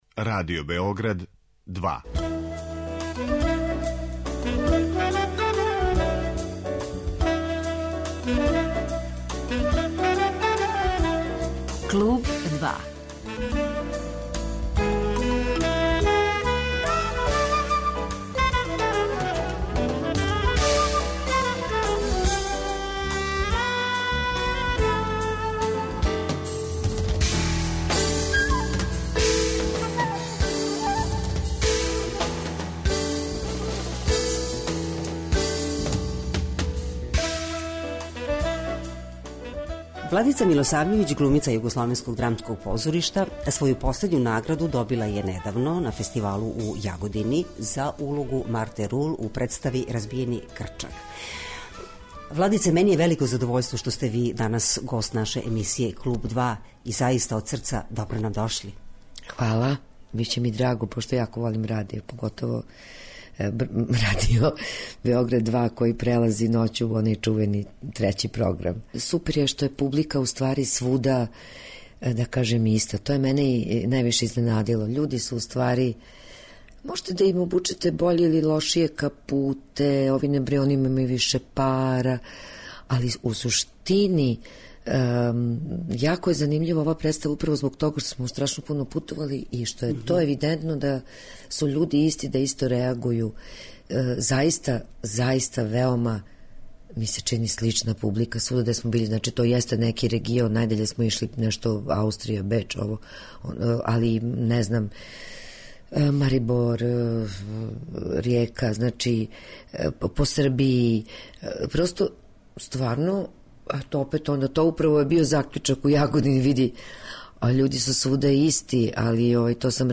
У разговору